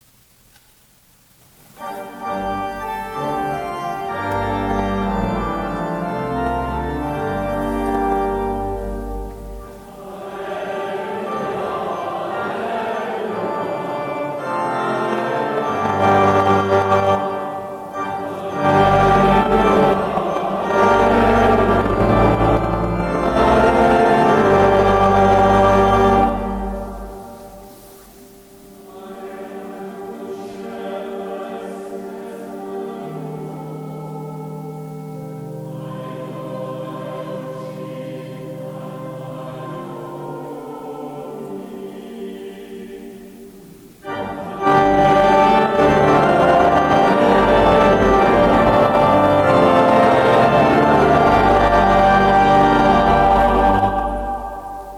Monastère bénédictin de Worth Abbey
Extraits des chants
Psaume (25 sec)
Alleluia